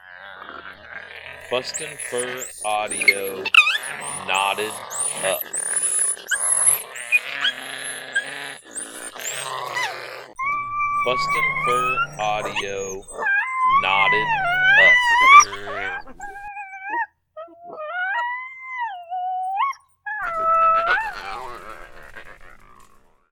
Apollo and Beans were recently paired together as a breeding pair and as usual, the fighting and bickering ensued.  Packed with growls, squalls and yips, making it an excellent sound to run during the territorial season.
• Product Code: pups and fights